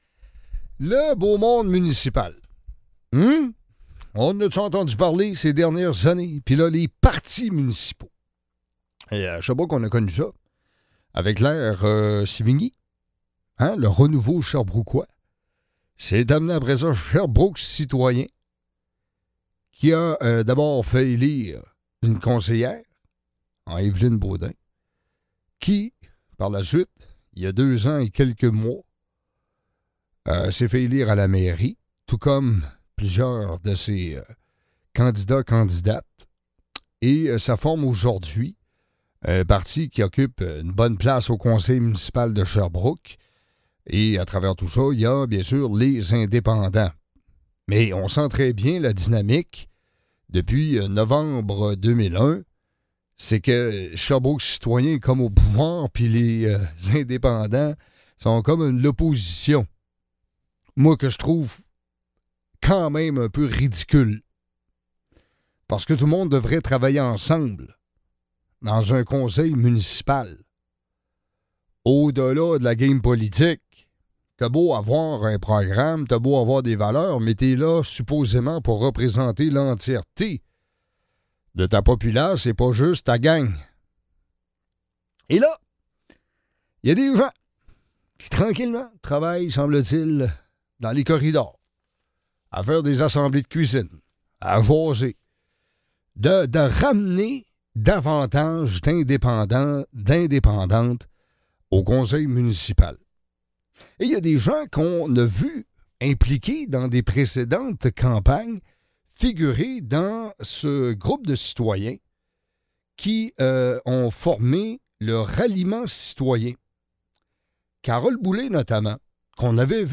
Entrevue